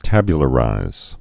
(tăbyə-lə-rīz)